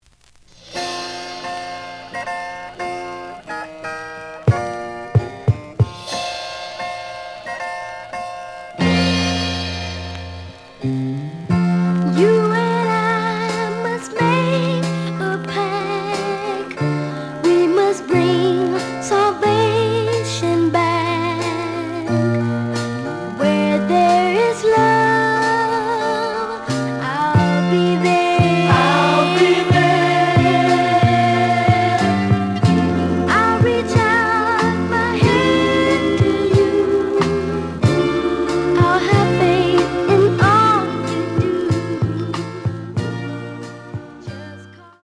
The audio sample is recorded from the actual item.
●Format: 7 inch
●Genre: Soul, 70's Soul